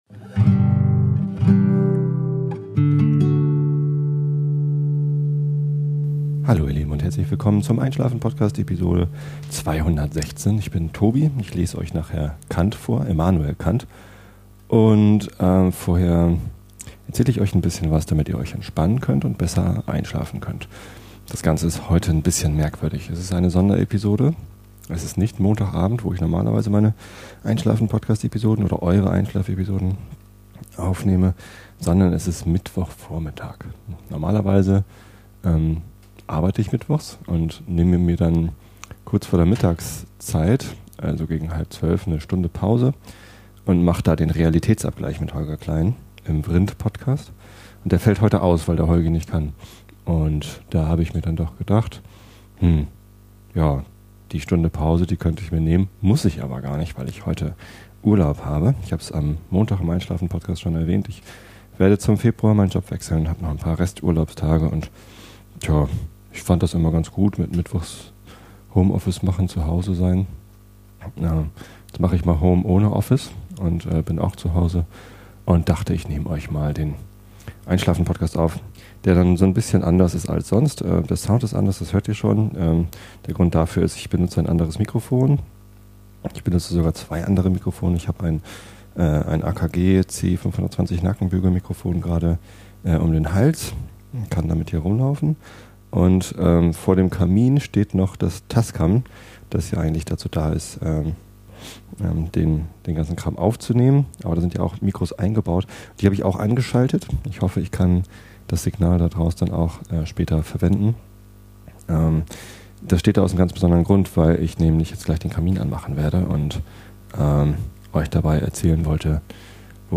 Beschreibung vor 13 Jahren Unterschiedlicher kann es kaum laufen: erst wird ganz entspannt der Kamin befeuert, und dabei in möglichst langweiliger, epischer breite erklärt, warum und wie und was. Danach geht es dann in ein bewegenderes Thema, nämlich die Wahl in Niedersachsen, beziehungsweise um das Wahlsystem, welches mich vor große Rätsel stellt.